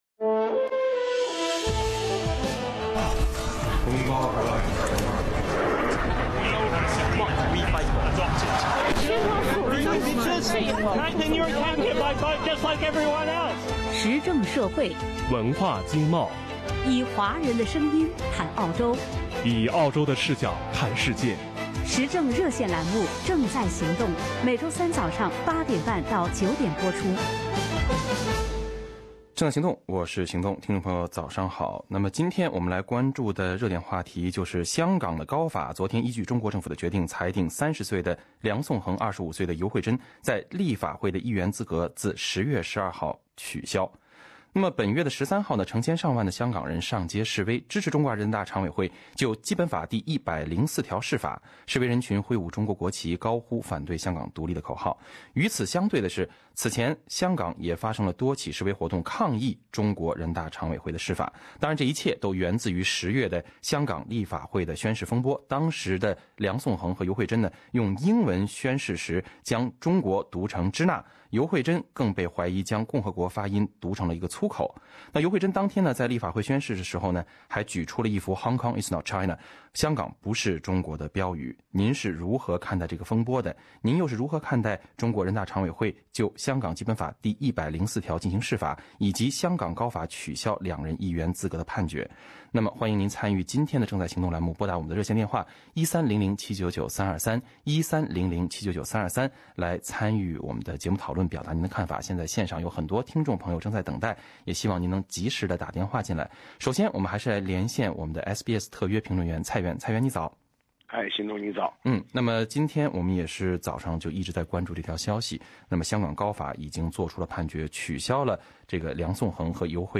听众热议此次香港高法的判决。